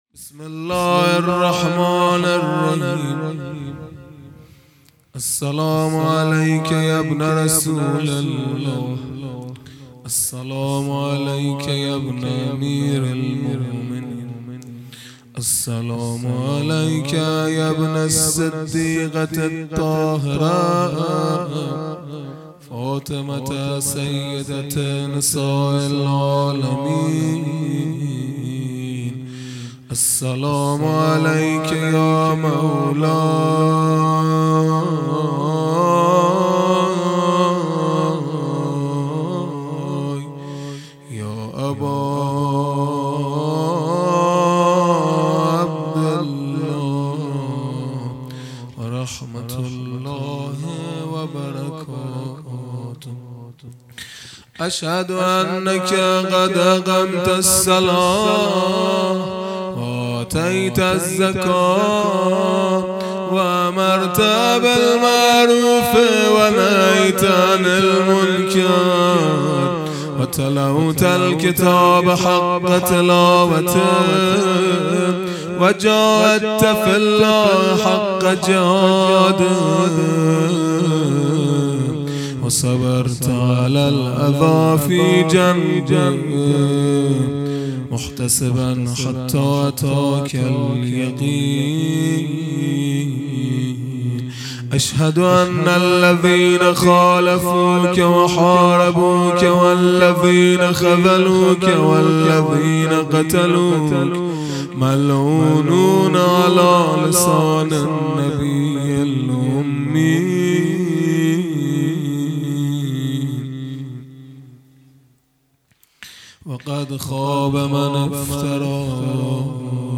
خیمه گاه - هیئت بچه های فاطمه (س) - مناجات | زیارت امام حسین(علیه السّلام) | ۴ اردیبهشت ۱۴۰۱
شب سوم قدر